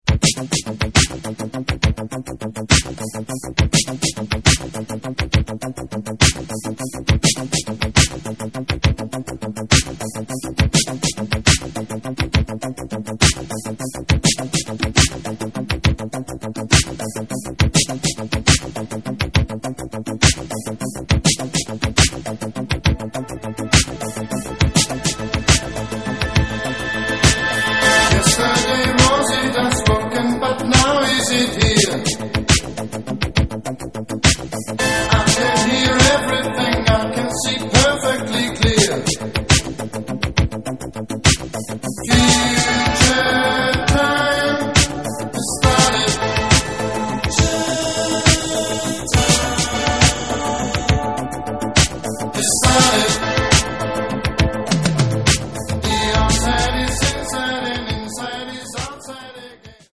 вокал, бас, перкуссия, клавишные